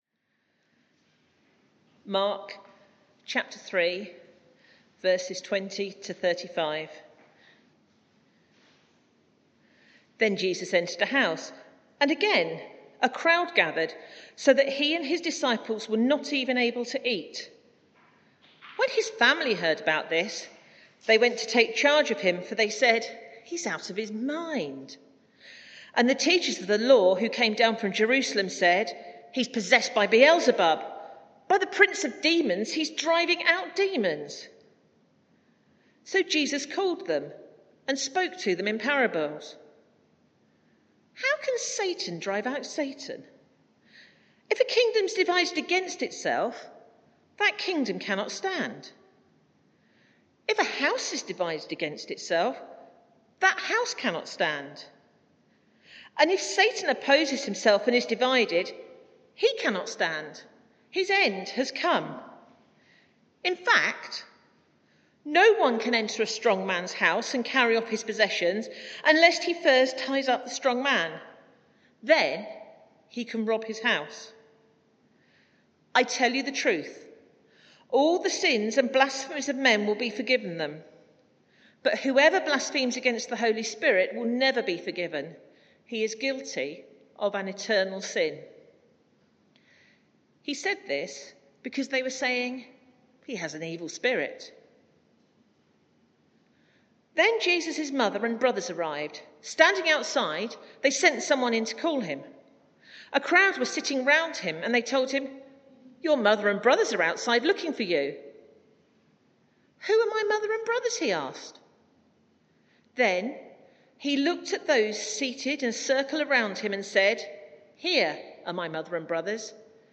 Media for 11am Service on Sun 24th Oct 2021 11:00 Speaker
Sermon